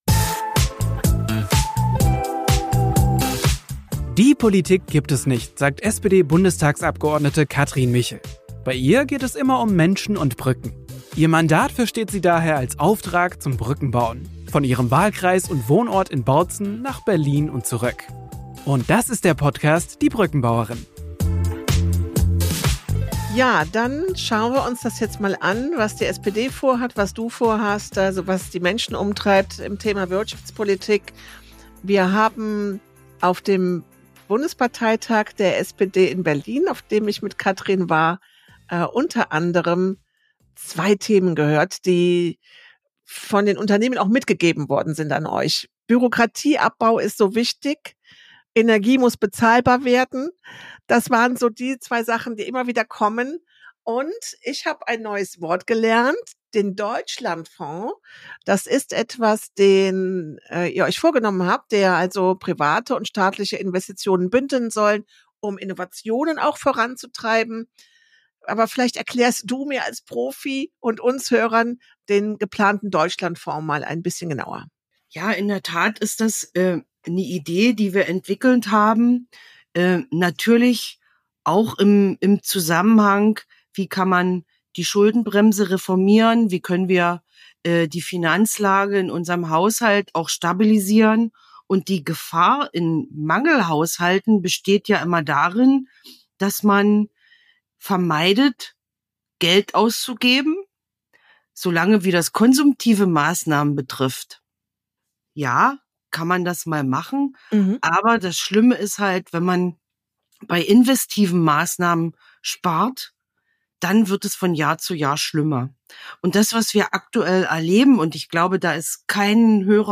Die Haushaltsexpertin im Bundestagsausschuss Kathrin Michel stellt in dieser Folge den Deutschlandfonds und geplante Maßnahmen zum Bürokratieabbau vor. Wieso ist es auch für die Wirtschaft wichtig, an Energiewende und Klimaschutz weiter zu arbeiten und wie bleibt das für Bürgerinnen und Bürger bezahlbar? Zentrale Themen sind außerdem der Fachkräftemangel sowie eine Arbeitsmarktpolitik, die auch auf den demografischen Wandel reagiert.